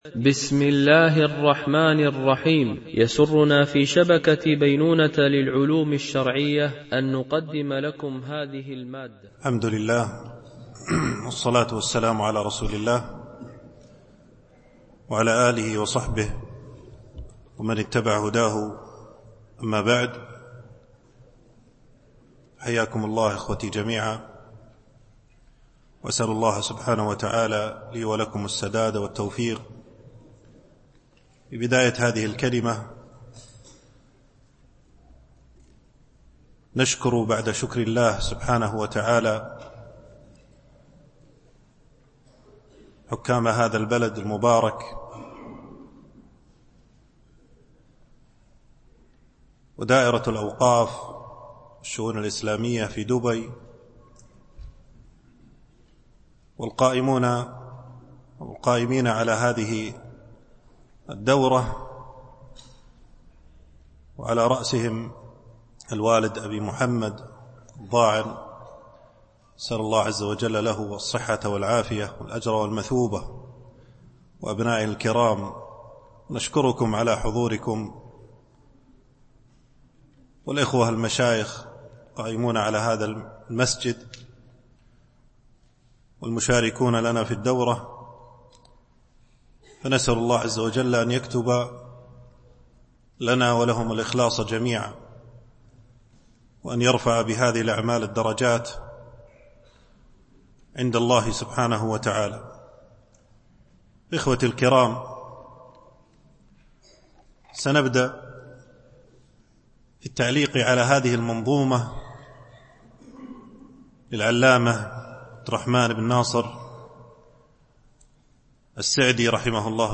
شرح منظومة السير إلى الله والدار الآخرة ـ الدرس 1
دورة علمية شرعية
بمسجد عائشة أم المؤمنين - دبي